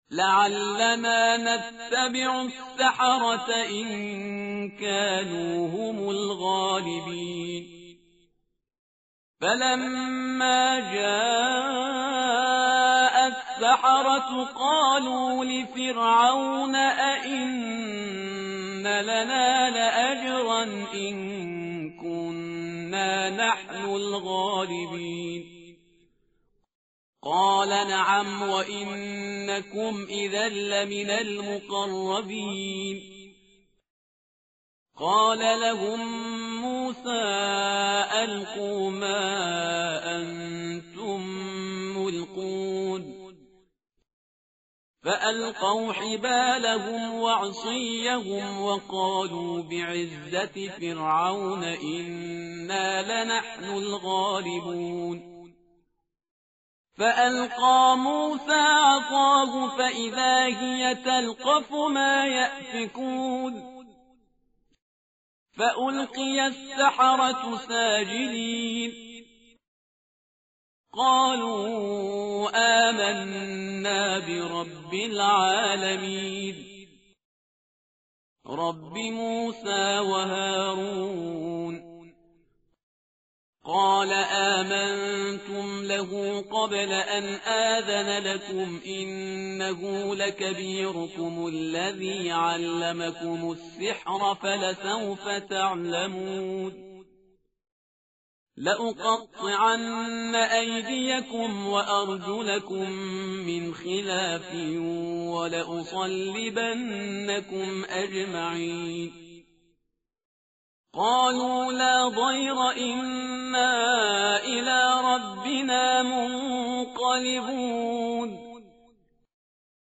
متن قرآن همراه باتلاوت قرآن و ترجمه
tartil_parhizgar_page_369.mp3